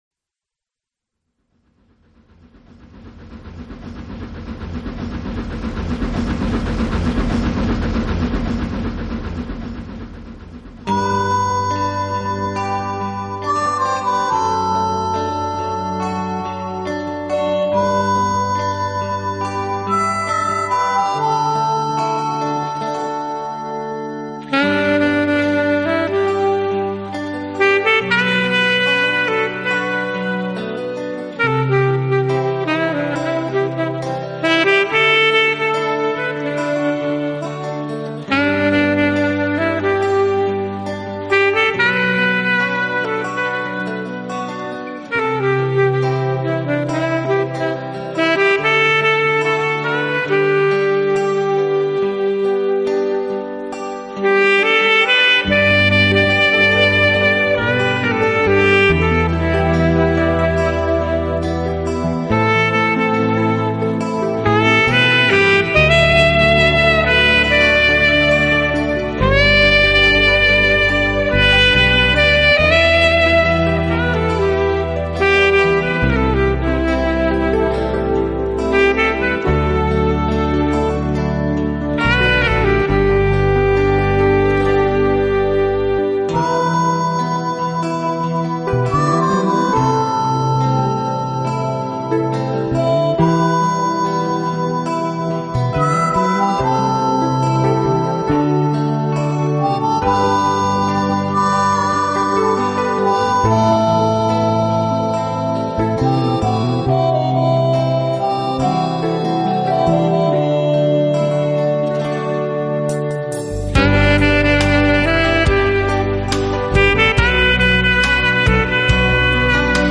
专辑语种：萨克斯纯音专辑